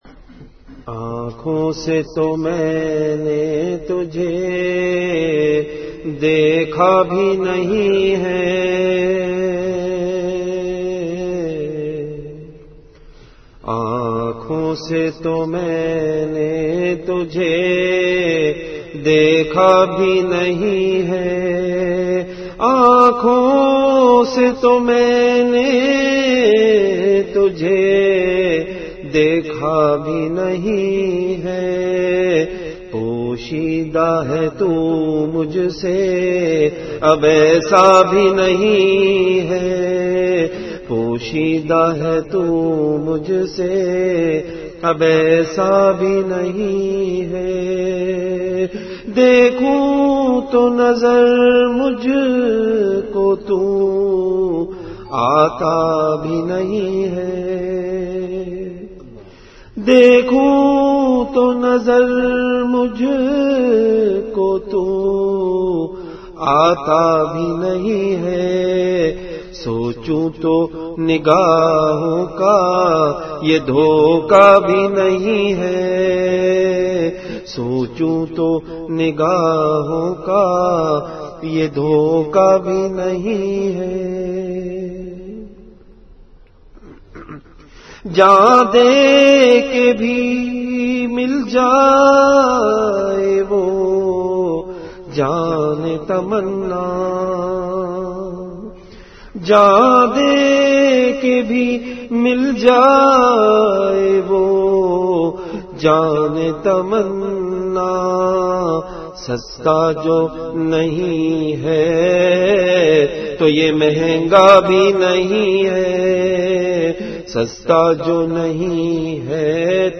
Delivered at Makkah Mukarrama.